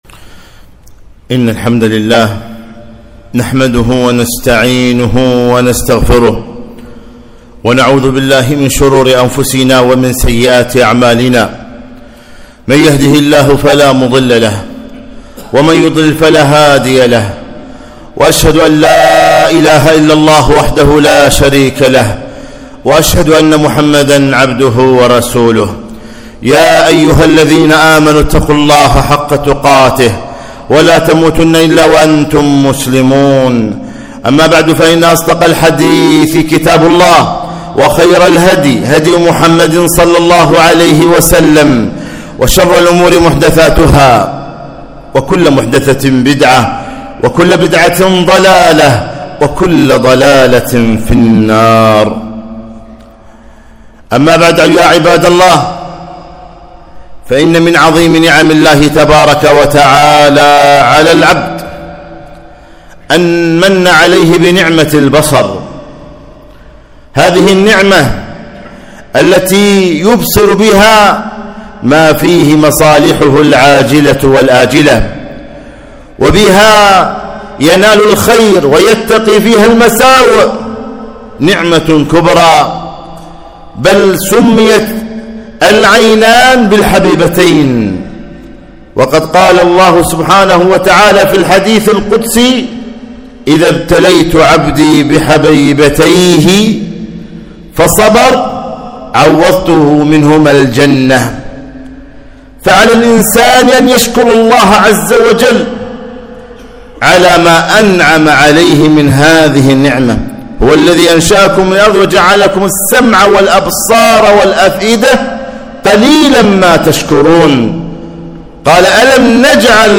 خطبة - لا تتبع النظرة النظرة